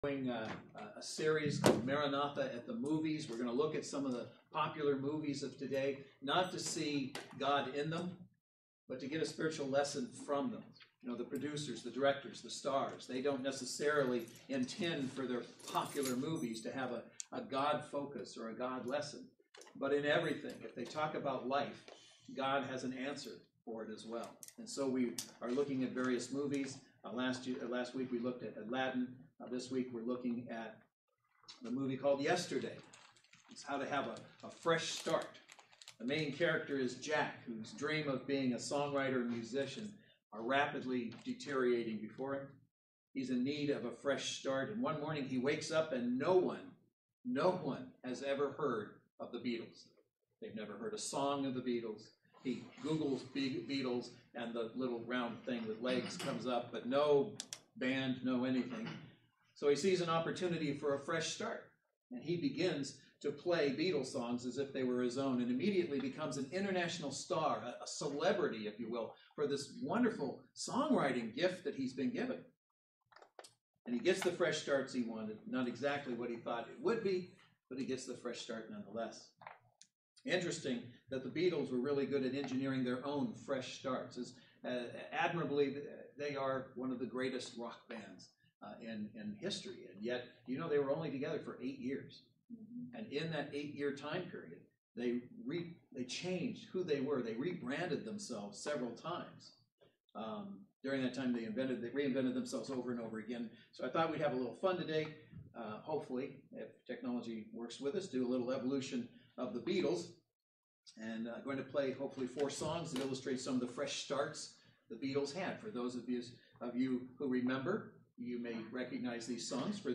Service Type: Saturday Worship Service